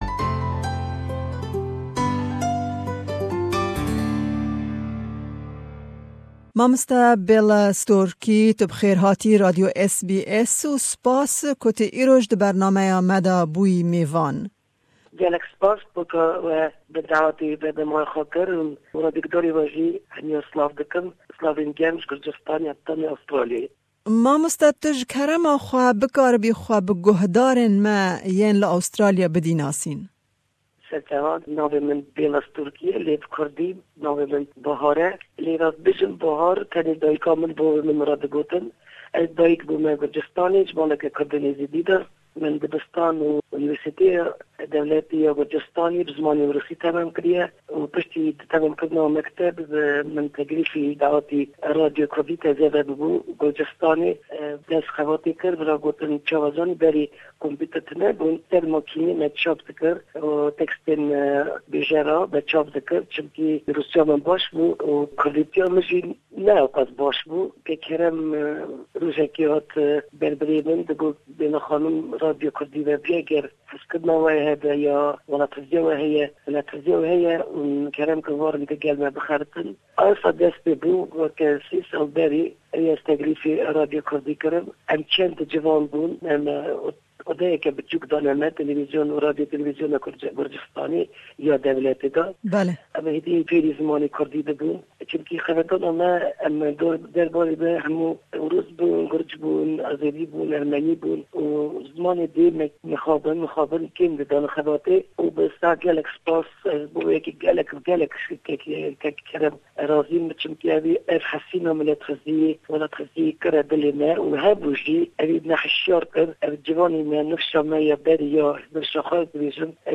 Me derbarî jiyan, kar û astengiyên di destpêka jiyana wêye rojnamevantiyê û babetên din jî hevpeyvînek